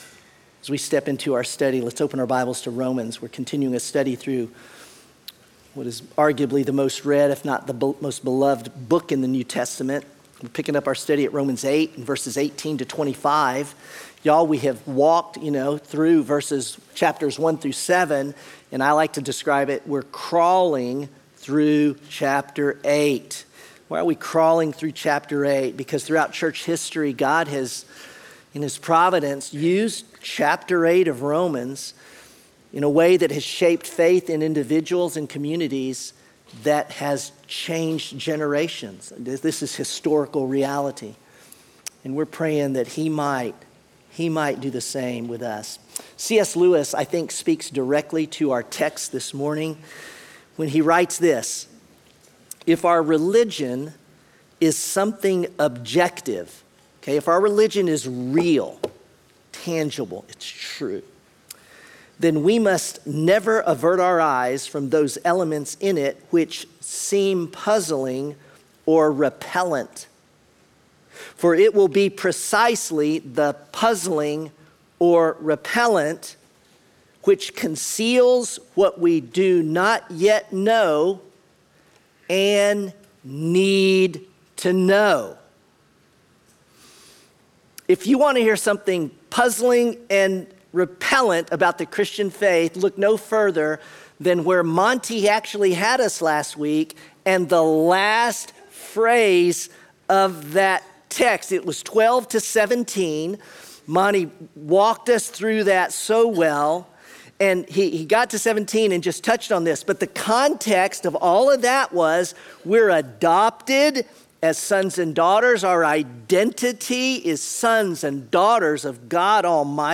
Sermon Unshakeable: Romans 8